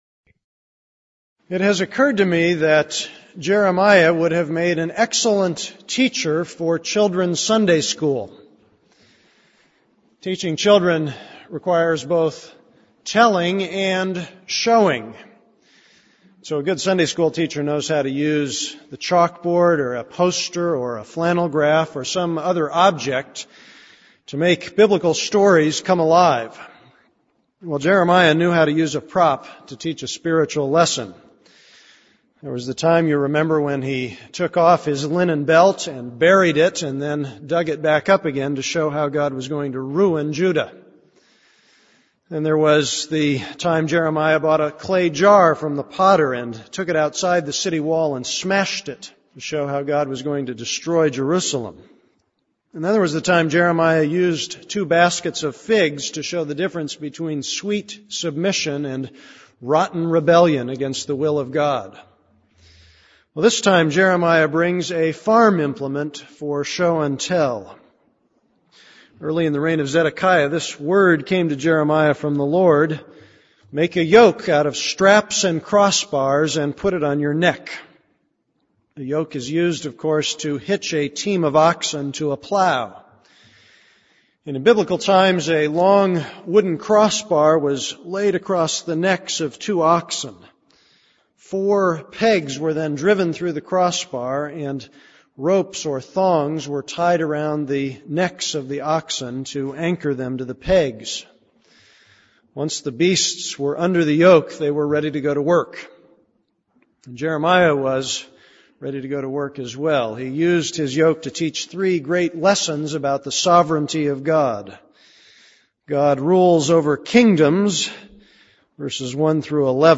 This is a sermon on Jeremiah 27:1-22.